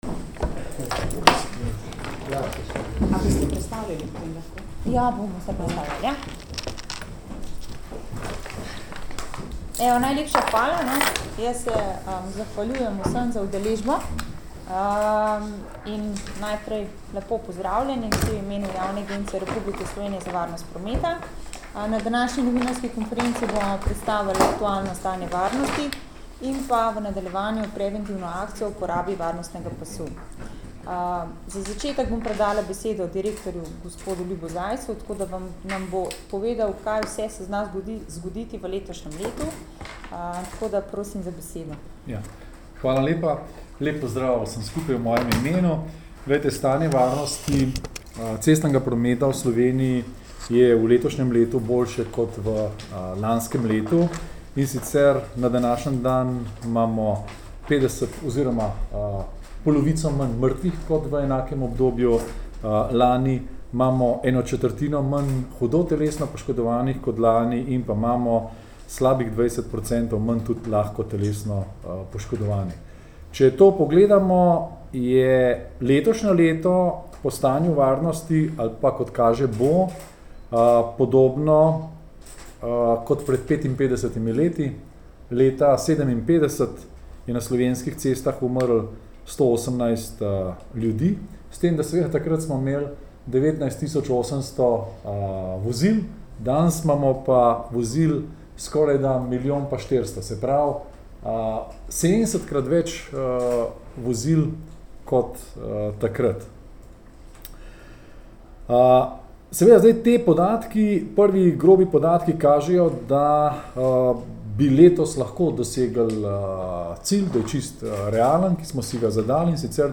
Novinarska konferenca Preventivne akcije — ZA UPORABO VARNOSTNEGA PASU! —
posnetek novinarska konferenca Varnostni pas.MP3